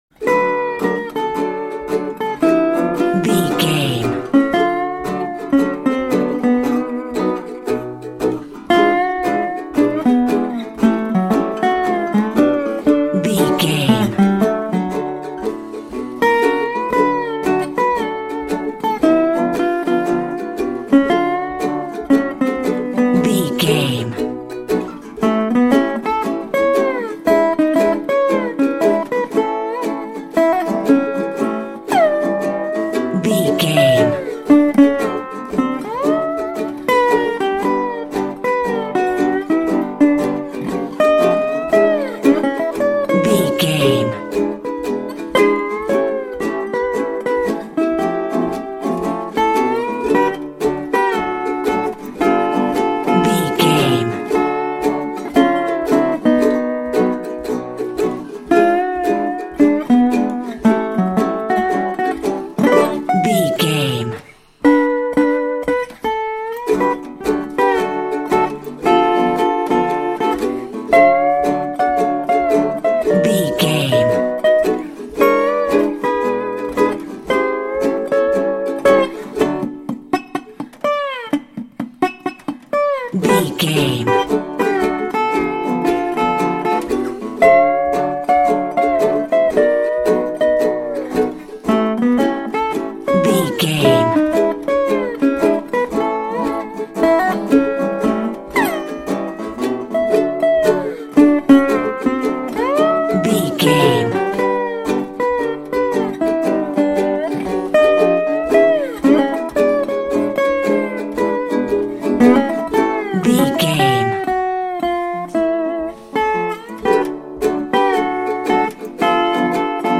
Uplifting
Ionian/Major
acoustic guitar
bass guitar
ukulele
dobro
slack key guitar